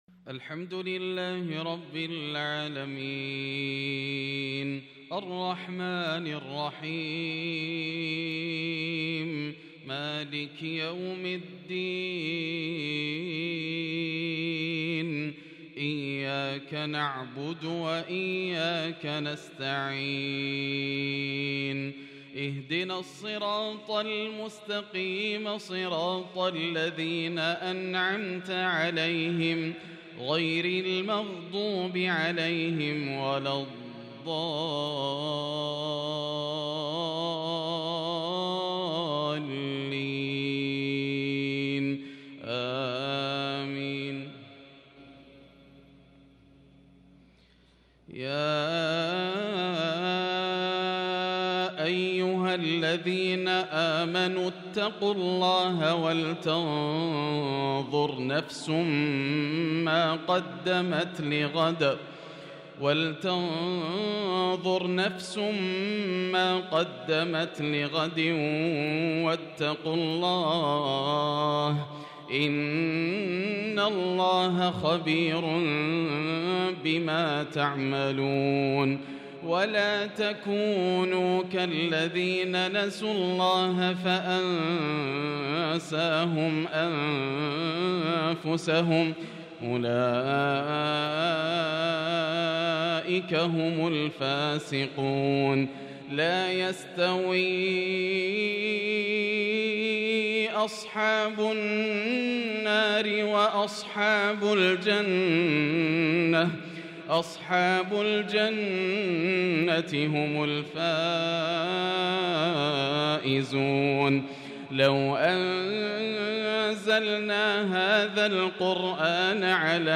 عشاء الاثنين 2-5-1443هـ أخر سورة الحشر و سورة الليل | Isha prayer from Surah Al-Hashr & Al-lail 6/12/2021 > 1443 🕋 > الفروض - تلاوات الحرمين